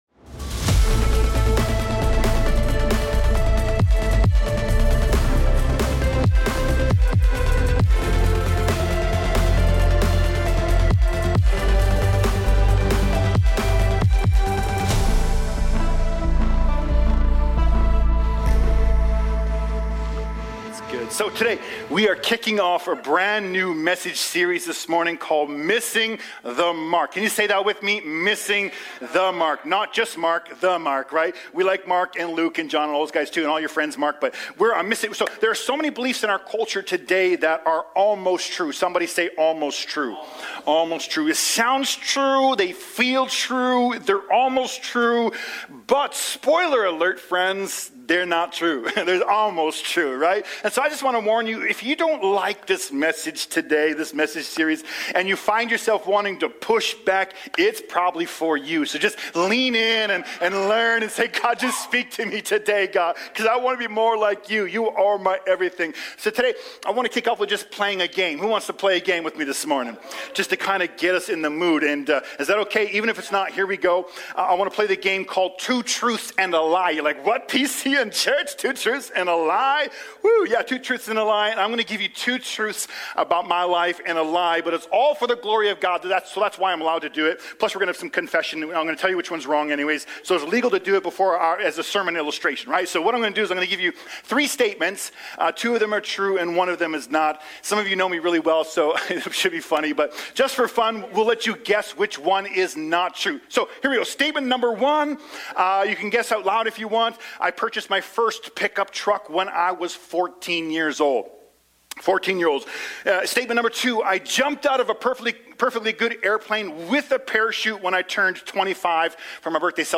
Sermons | Beloved City Church